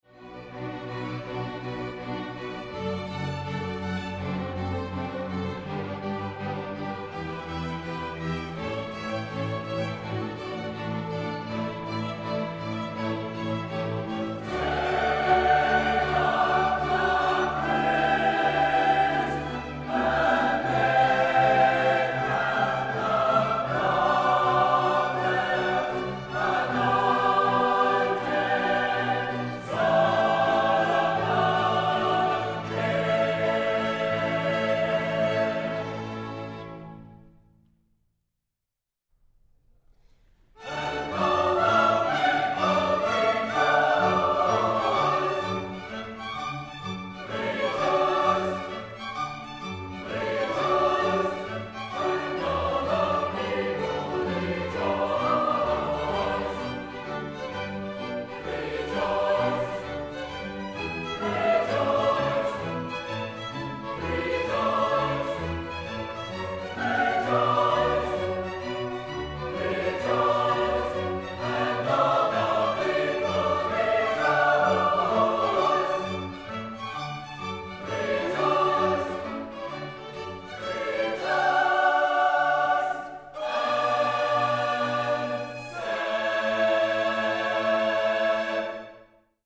(String Ensemble and Choir)